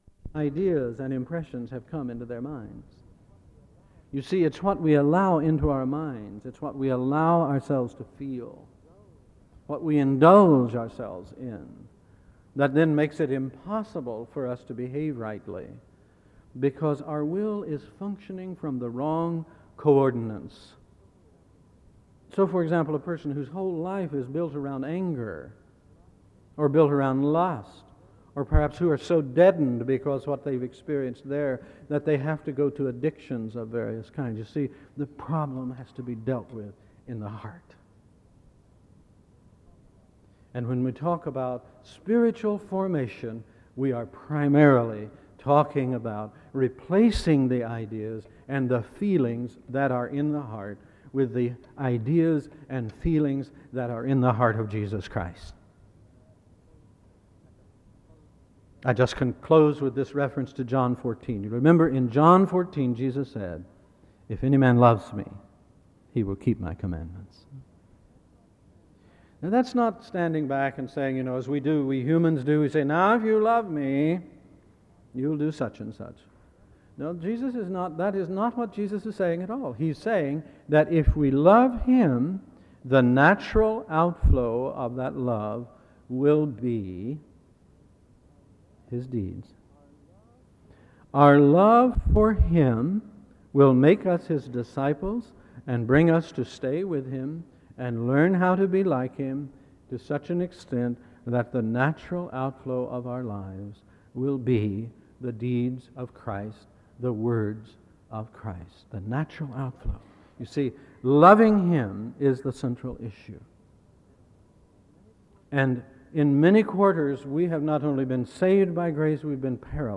SEBTS Page Lecture - Dallas Willard September 26, 1995